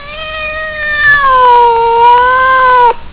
Cat 2
CAT_2.wav